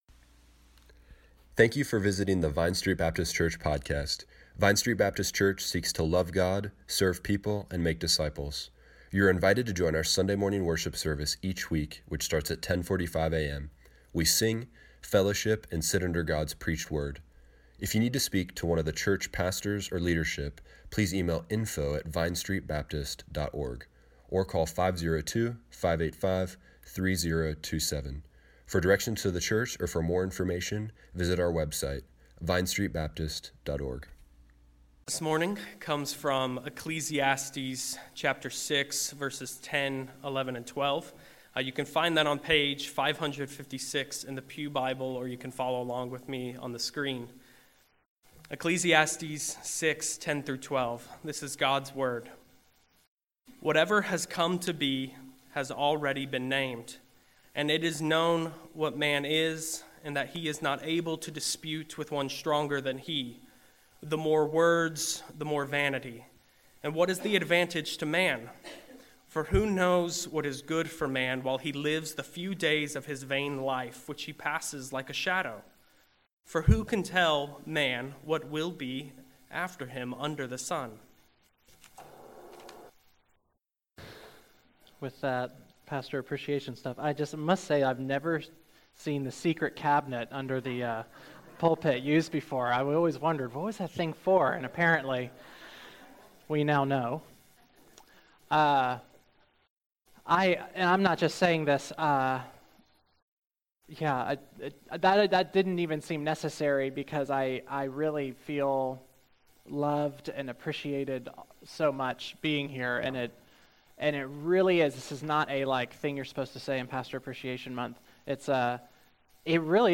Service Morning Worship
sermon